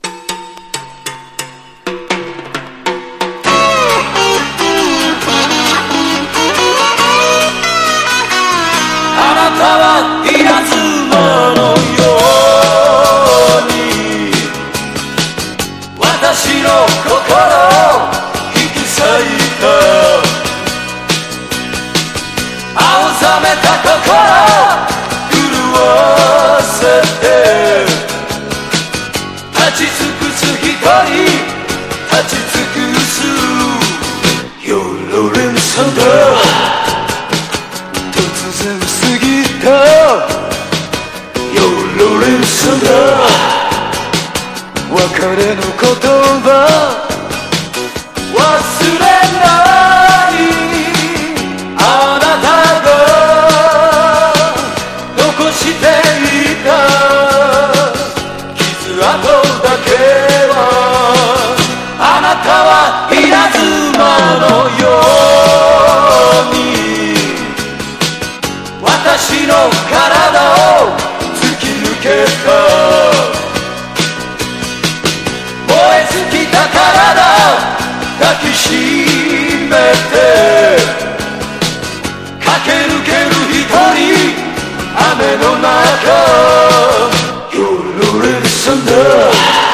60-80’S ROCK